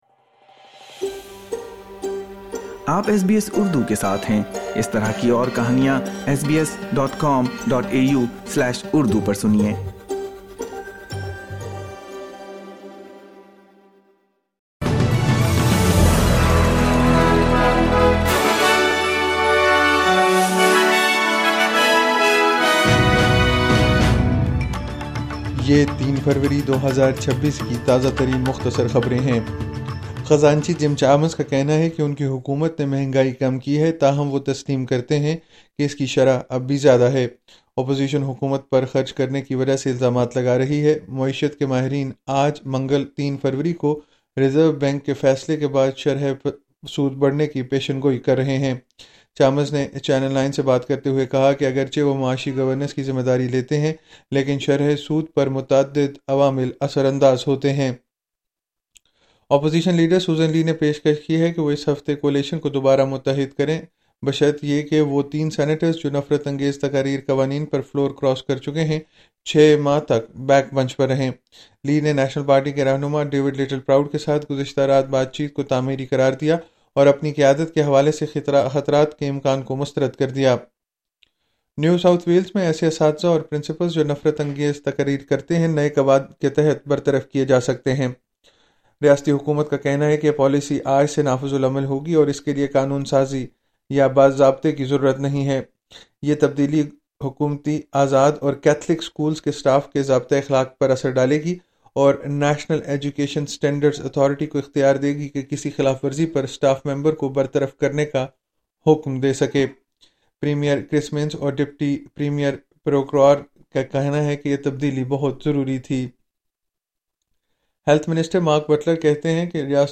مختصر خبریں : منگل 03 فروری 2026